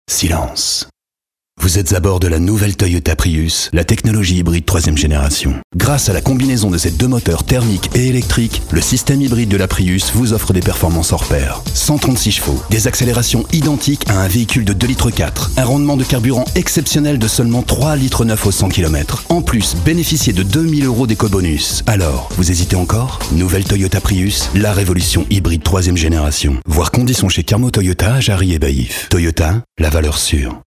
Nous pouvons créer pour vous les textes de vos spots publicitaires et réalisé ensuite l’enregistrement et le montage sur musique.